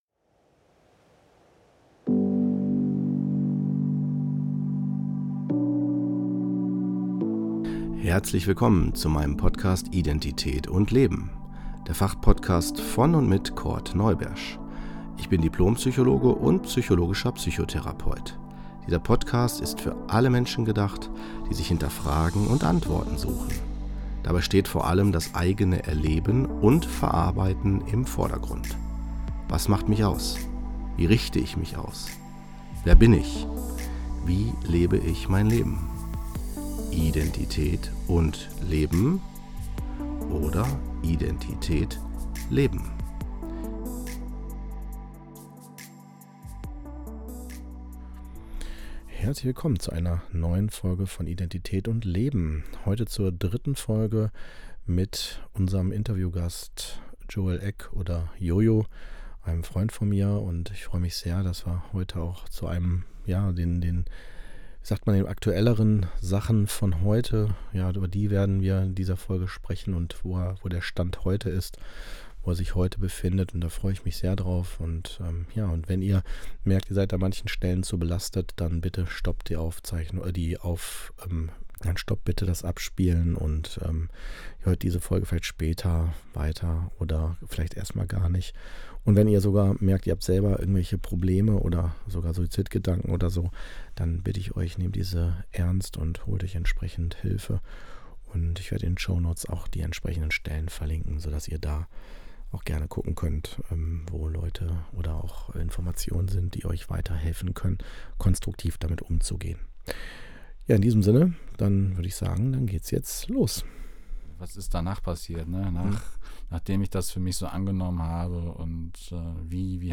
Das Interview ist in 3 Schwerpunkte aufgeteilt.